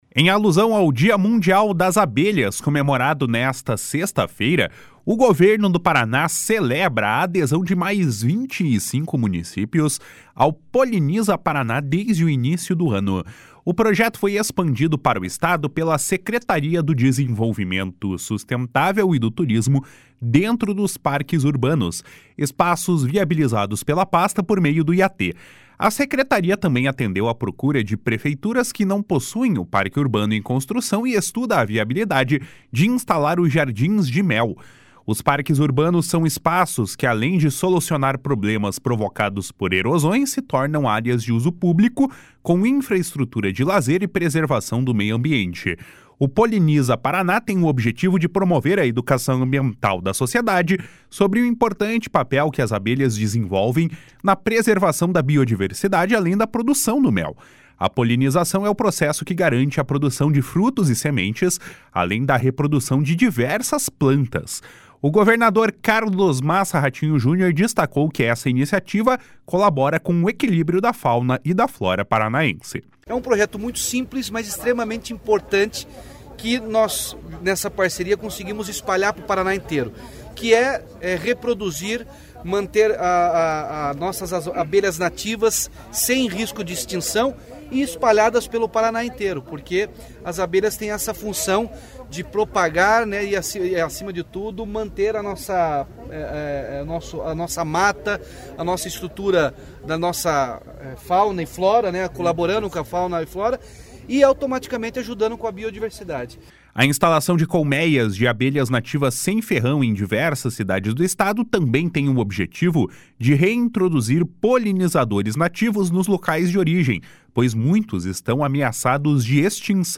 // SONORA RATINHO JUNIOR //